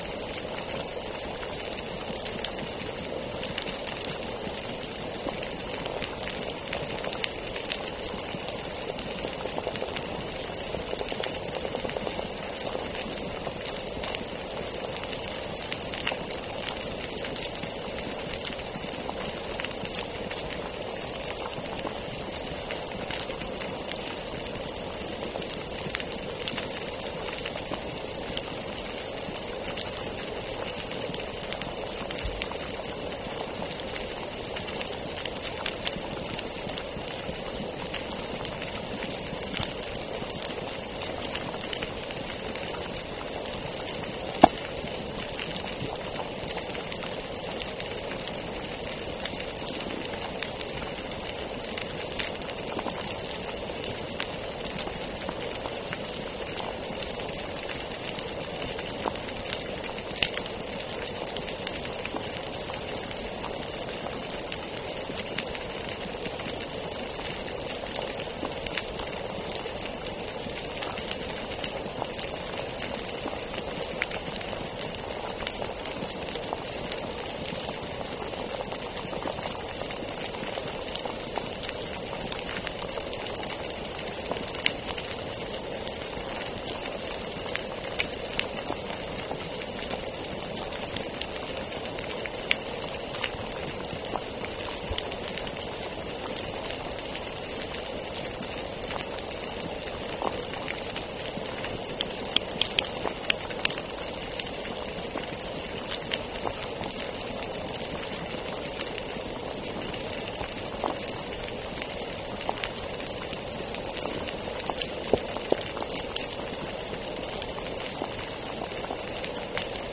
Demonstration soundscapes
biophony